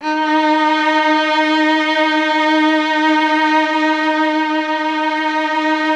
MELLOTRON .5.wav